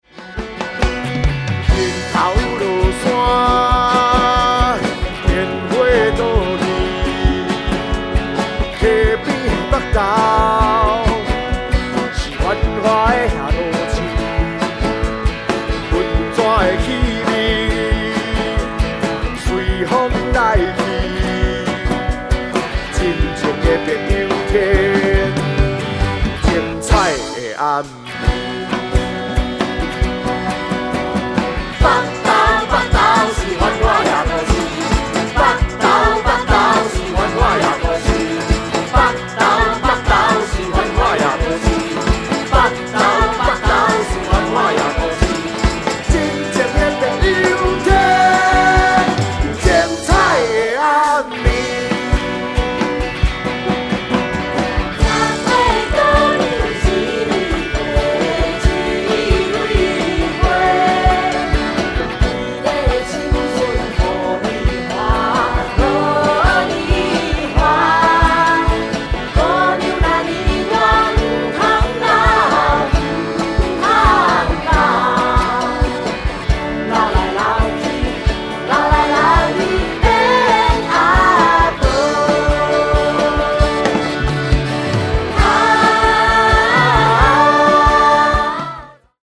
完整收錄音樂劇現場的感動，收錄18首歌曲，值得所有劇迷及歌迷的珍藏。